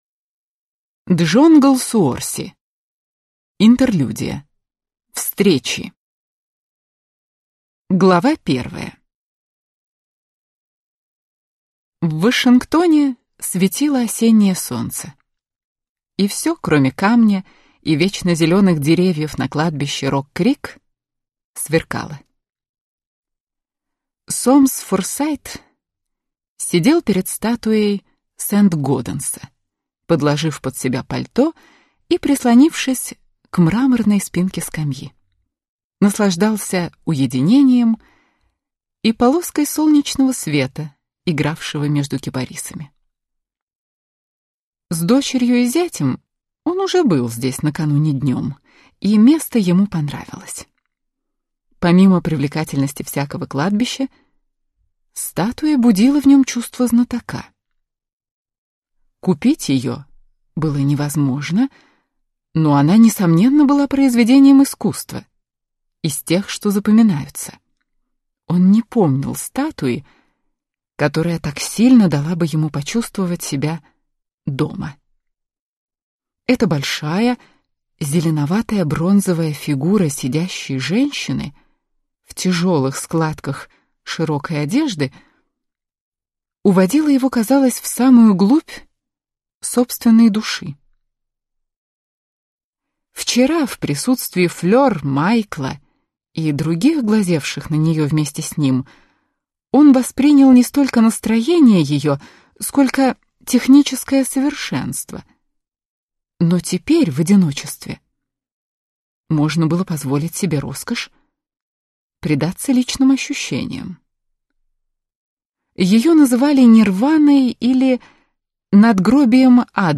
Аудиокнига Сага о Форсайтах: Лебединая песня | Библиотека аудиокниг